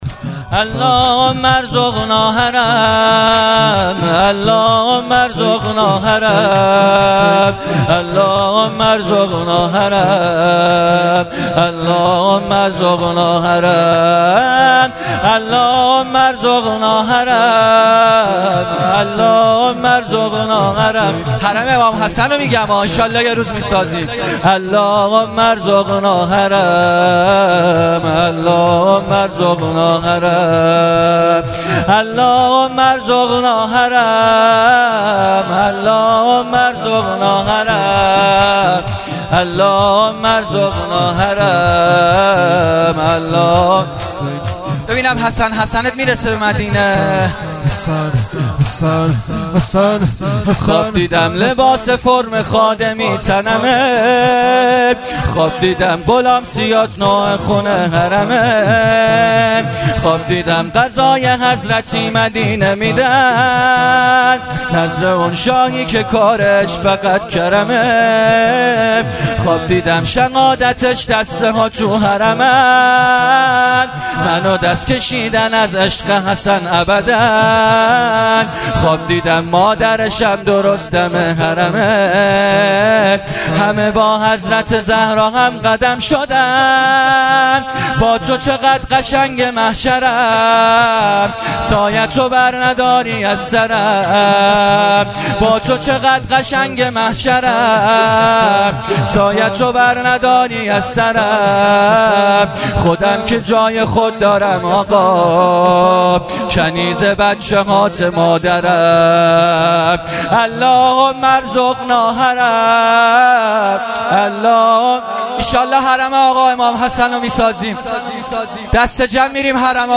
شور
شب ششم محرم